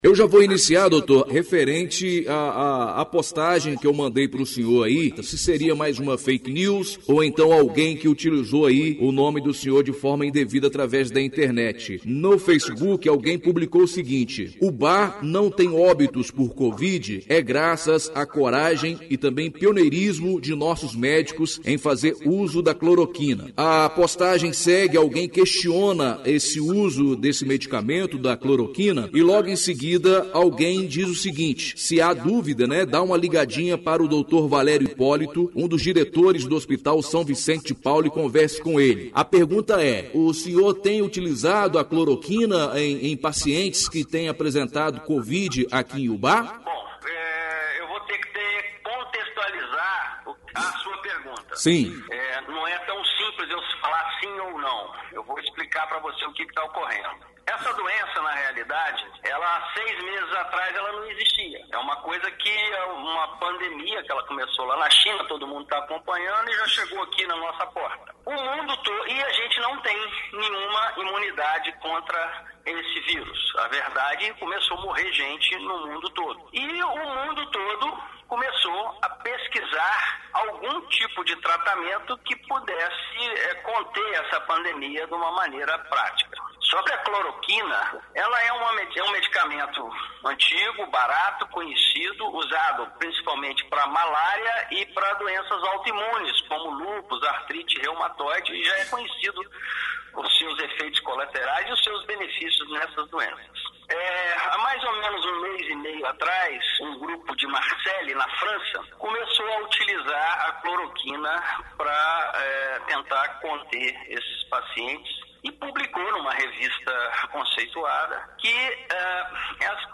Entrevista exibida na Rádio Educadora AM/FM